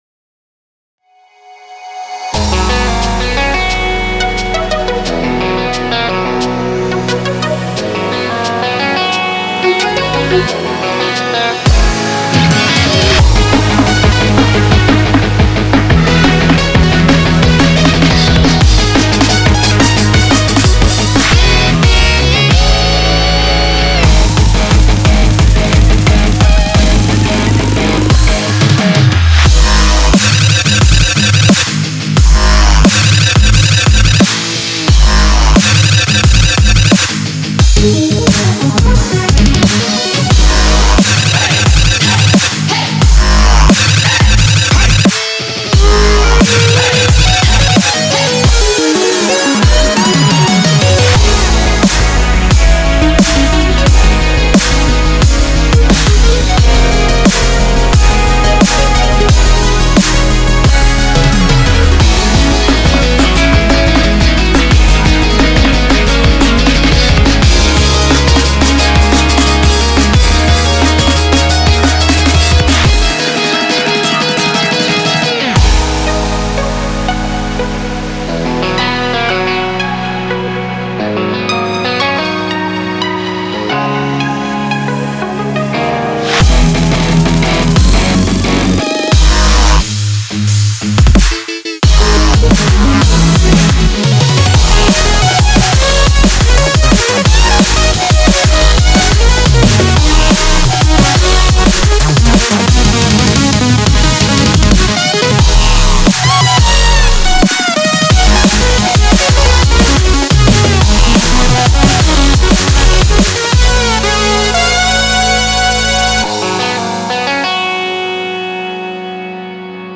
BPM89-177
Audio QualityPerfect (High Quality)